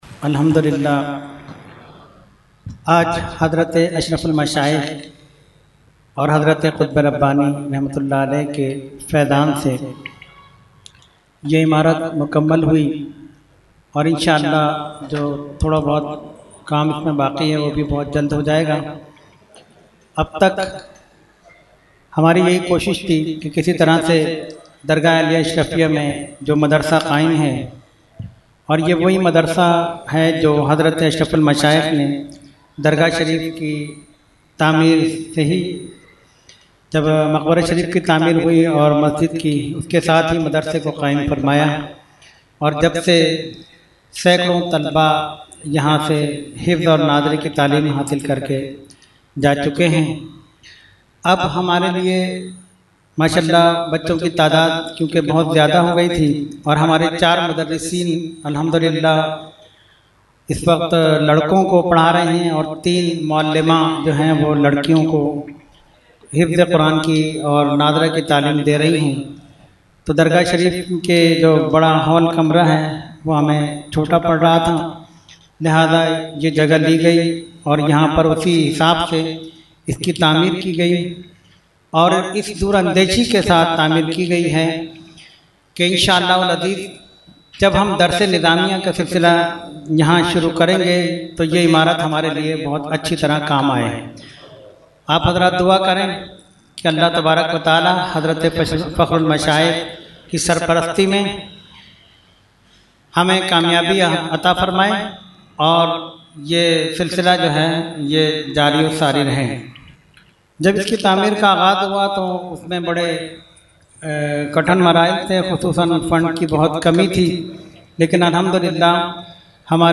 Category : Speech | Language : UrduEvent : Khatam Hizbul Bahr 2019